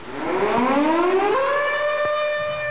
airraid.mp3